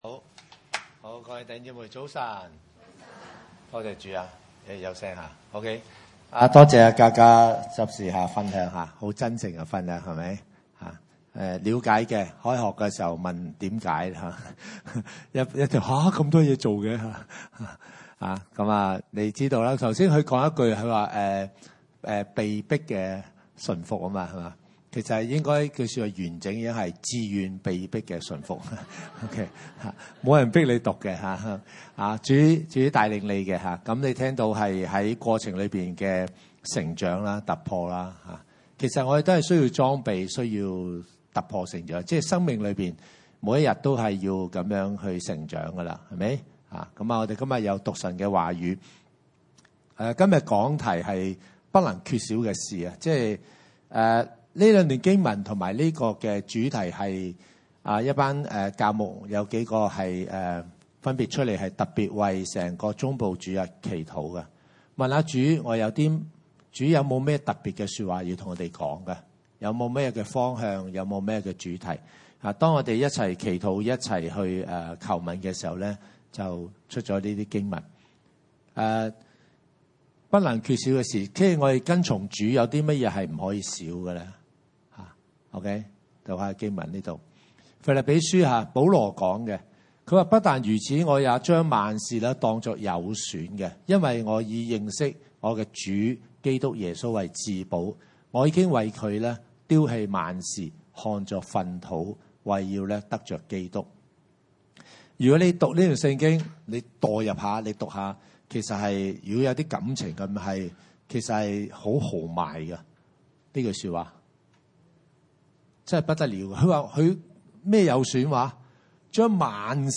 經文: 腓立比書 3：8 申命記 10：12-13 崇拜類別: 主日午堂崇拜 腓立比書 3 ： 8 不但如此，我也將萬事當作有損的， 因我以認識我主基督耶穌為至寶。